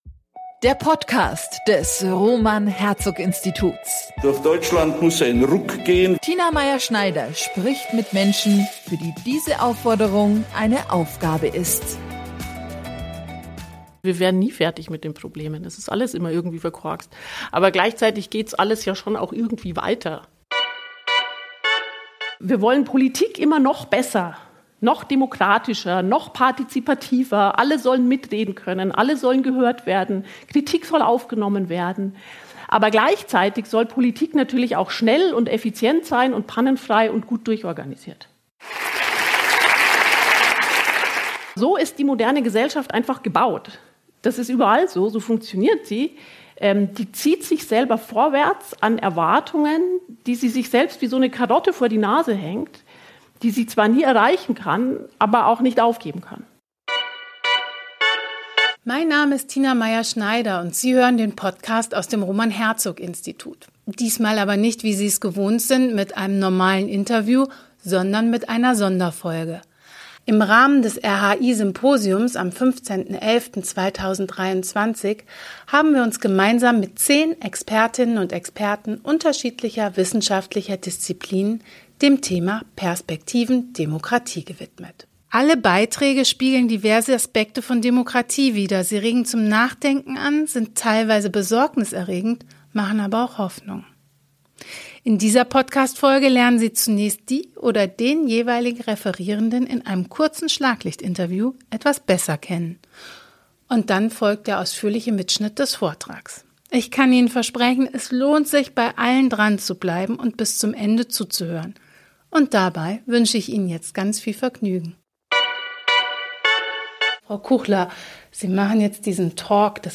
Im Rahmen des RHI-Symposiums am 15. November 2023 haben wir uns gemeinsam mit neun Expertinnen und Experten unterschiedlicher wissenschaftlicher Disziplinen dem Thema „Perspektiven Demokratie“ gewidmet. Ihre Beiträge spiegeln diverse Aspekte von Demokratie wider, sie regen zum Nachdenken an, sind teils durchaus besorgniserregend und machen teils Hoffnung.
Dann folgt der ausführliche Mitschnitt des Vortrags.